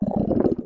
croak_skrell.ogg